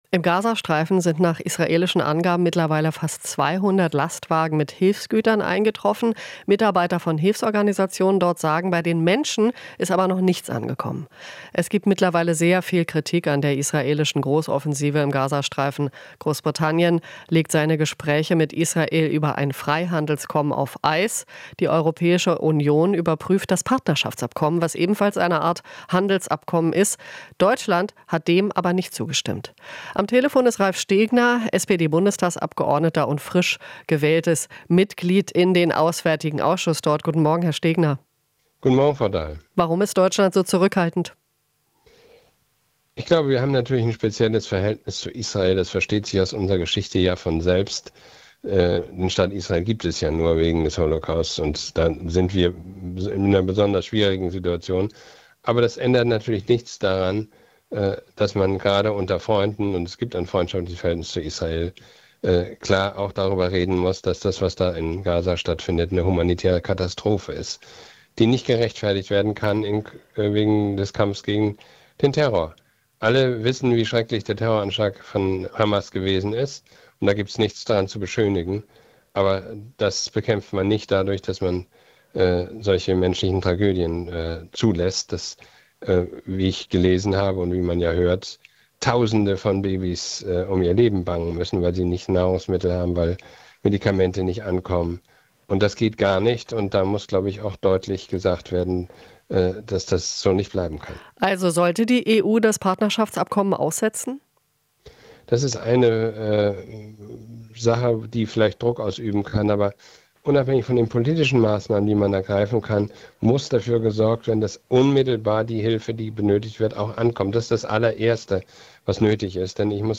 Interview - Stegner (SPD): Nicht Israel ist das Problem, sondern Netanjahu